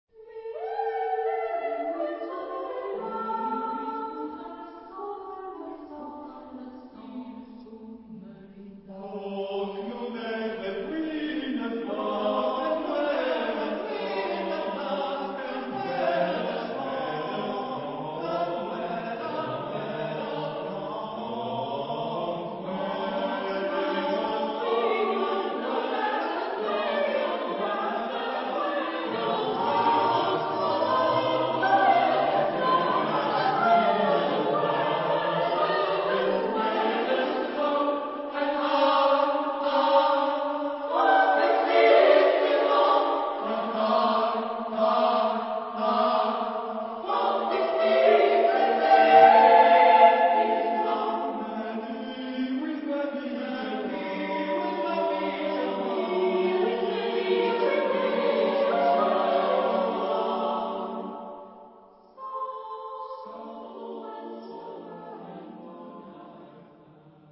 for unaccompanied voices
Genre-Style-Form: Secular ; Choir
Type of Choir: SSATB  (5 mixed voices )
Tonality: D major